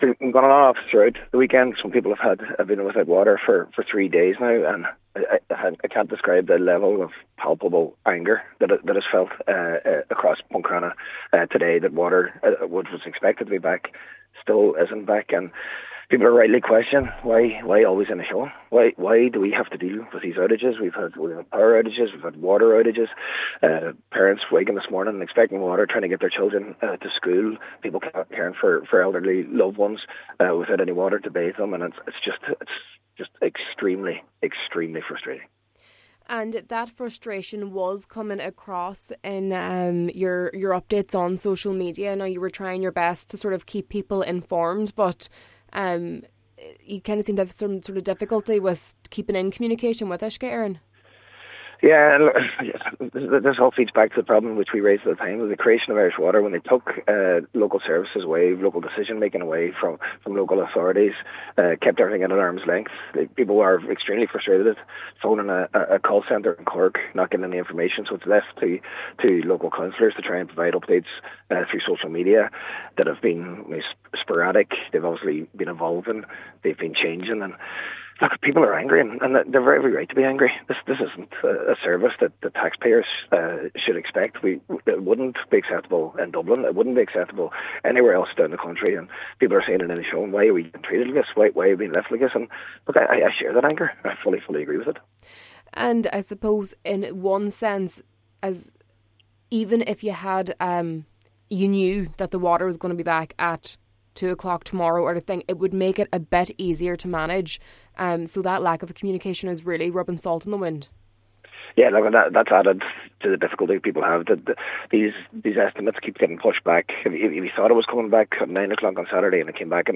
However, Cllr Murray said the goalposts were continuously moved over the weekend: